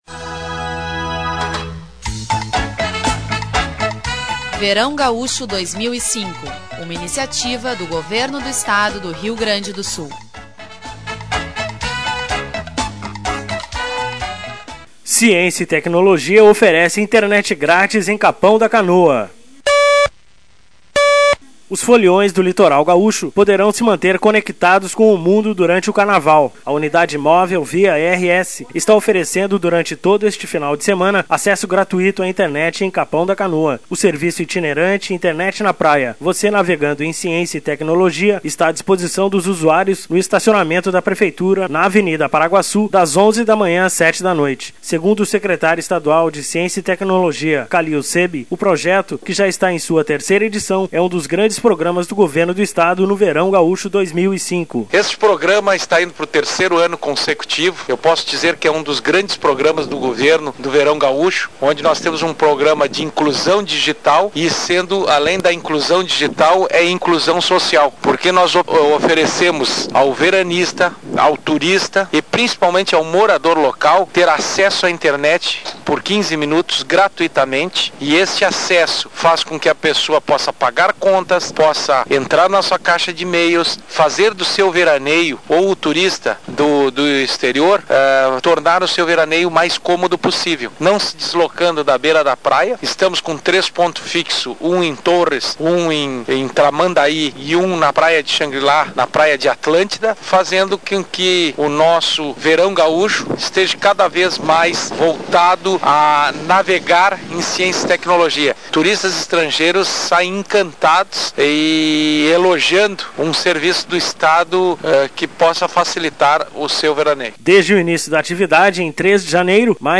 Os foliões do litoral gaúcho poderão se manter conectados com o mundo durante o Carnaval. A unidade móvel Via/RS está oferecendo, durante todo este final de semana, acesso gratuito à internet em Capão da Canoa. Sonora: Kalil Sehbe, secretário estadual